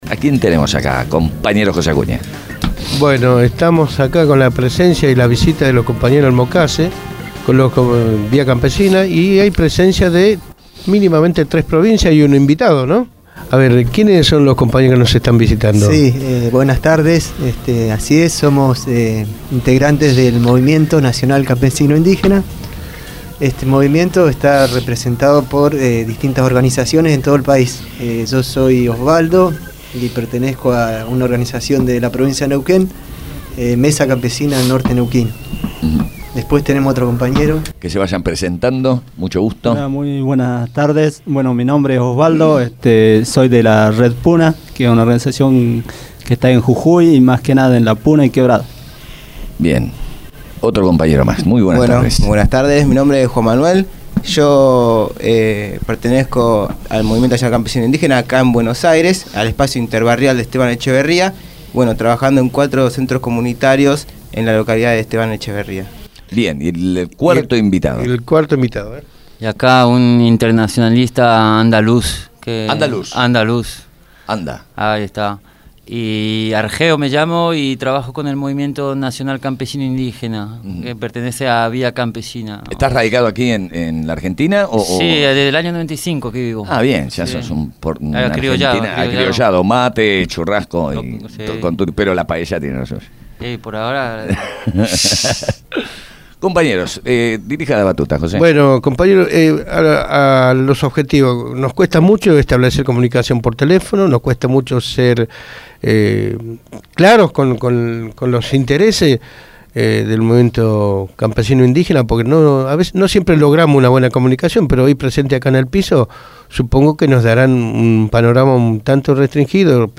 Militantes del Movimiento Campesino Indígena estuvieron en Abramos la Boca.